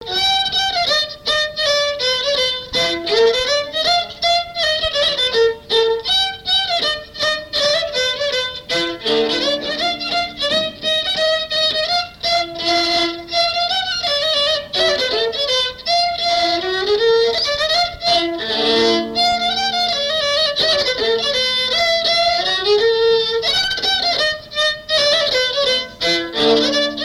Mémoires et Patrimoines vivants - RaddO est une base de données d'archives iconographiques et sonores.
danse : berline
Pièce musicale inédite